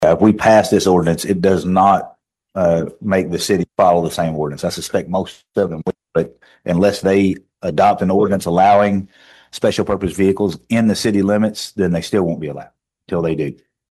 Hopkins County Fiscal Court is one step closer to allowing street-legal special purpose vehicles on certain local and state roads, following the approval of an ordinance on first reading at Tuesday morning’s meeting.